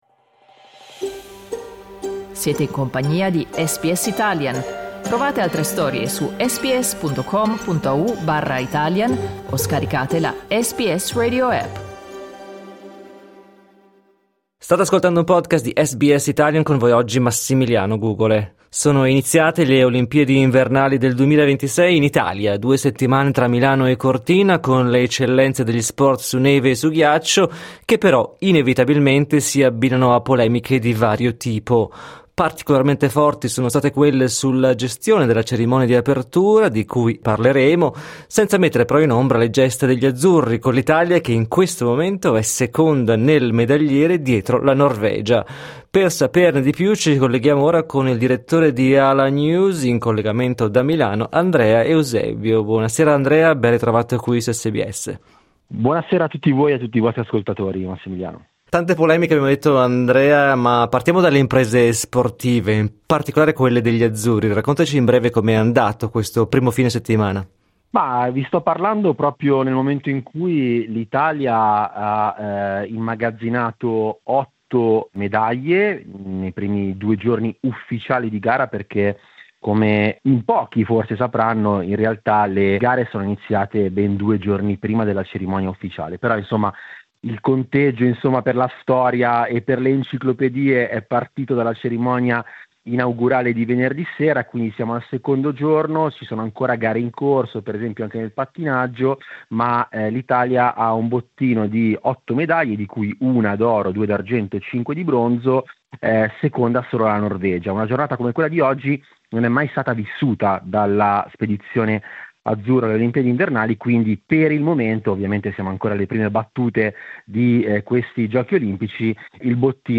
racconta da Milano ai microfoni di SBS Italian